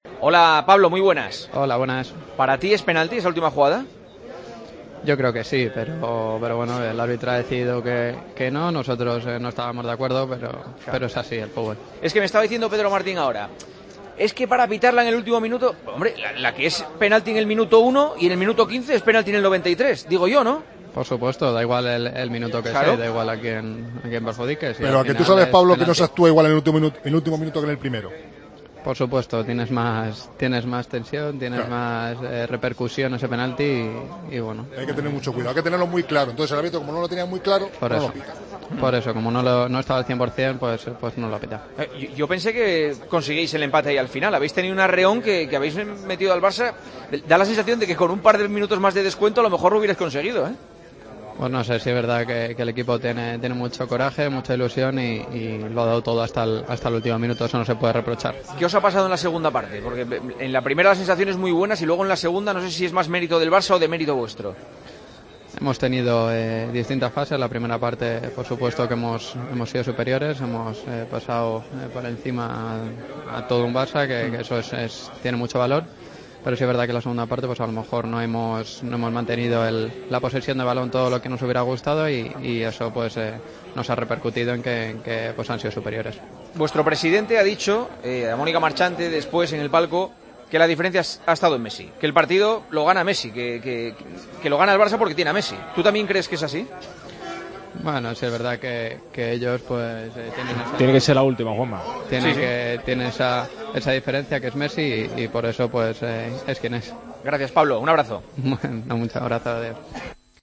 El jugador sevillista analizó la derrota ante el Barcelona en el Sánchez Pizjuán: "Creo que es penalti, pero el árbitro ha decidido que no. El equipo tiene mucho coraje y lo ha dado todo hasta el último minuto. En la primera parte heoms sido superiores, hemos pasado por encima a todo un Barça, pero en la segunda no hemos mantenido la posesión. Tienen la diferencia, que es Messi"